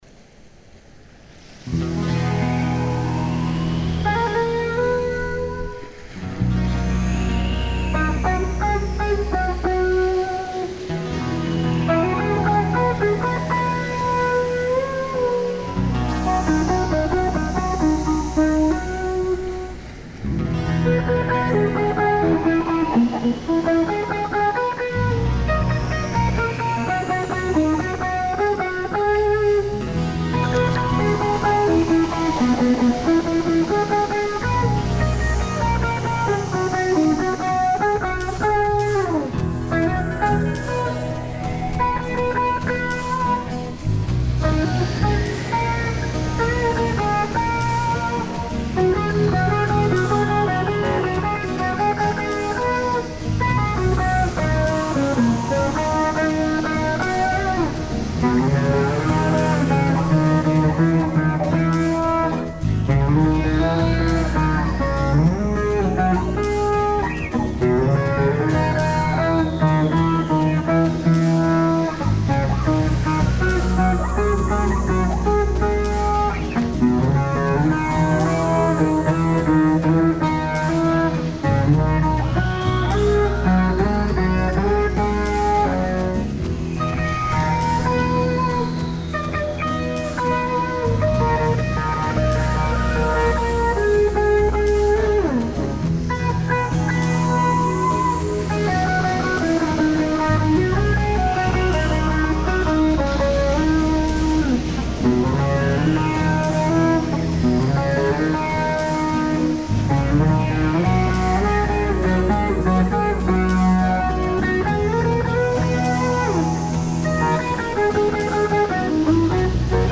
Version 2 – Chill Out Summer Mix
Es waren und sind nur 2 Akkorde 😉 und das ist auch gut so.
Diese Aufnahme ist ein „One-recording“ im H-Street Studio, unterstützt durch einen guten Rotwein und in einem Rutsch durchgespielt.
Für den Gitarrensound habe ich meine alte Strat und ein Zoom G9.2tt mit Santana patch und aufgedrehtem Z-Pedal genutzt.